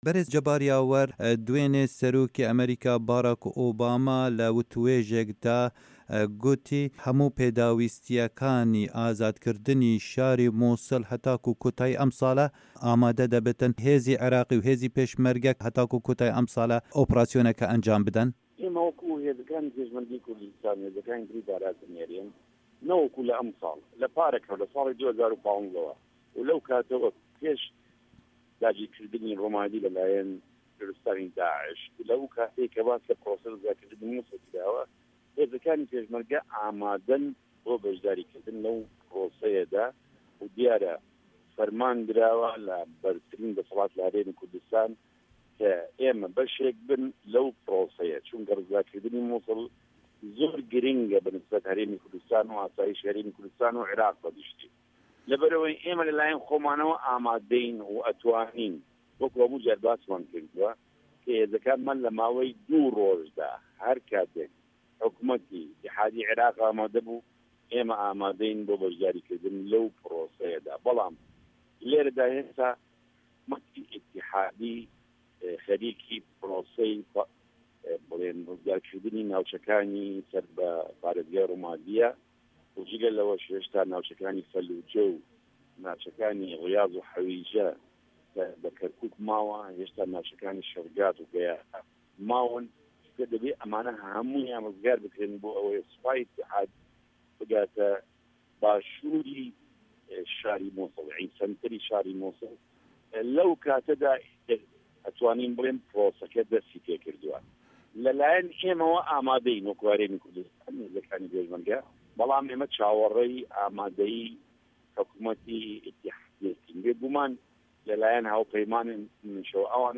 Temamiya hevpeyvîna dengê Amerîka pişka Kurdî li gel ferîq Cebar Yawer encam dayî di fayla deng de ye.